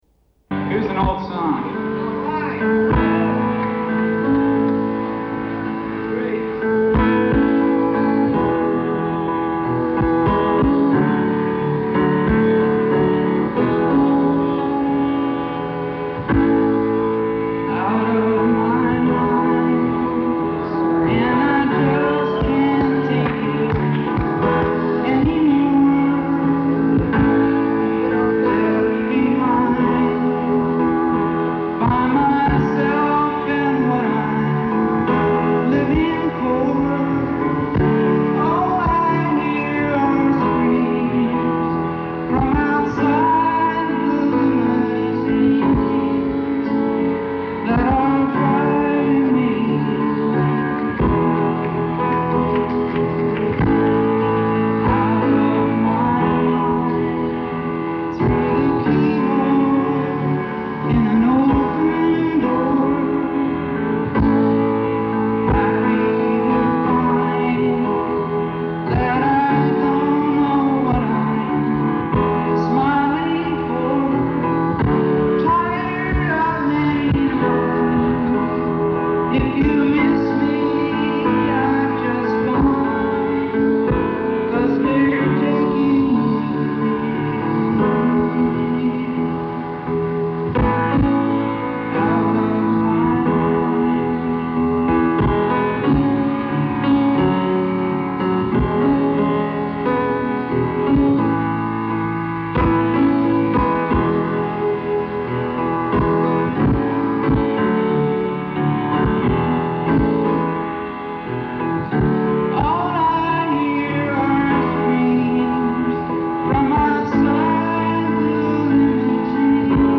1978 early show San Francisco